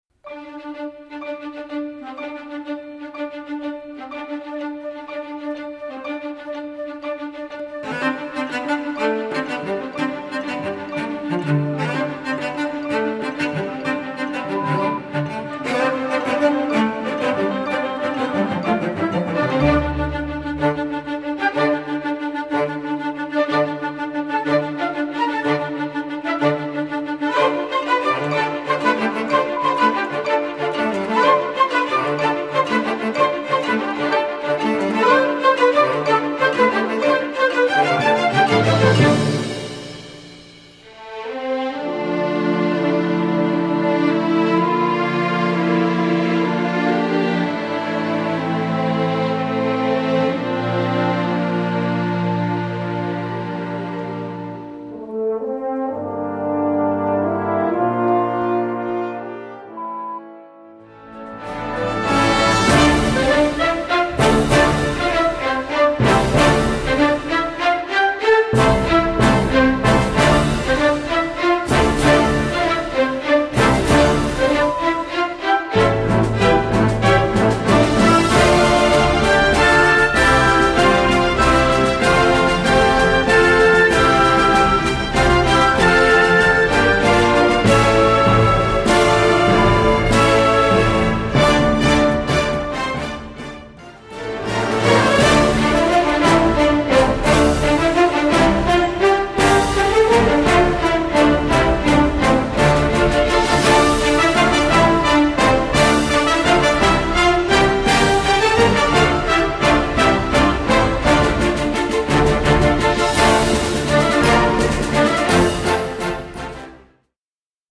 Gattung: Sinfonieorchester / Film & Musical
Besetzung: Sinfonieorchester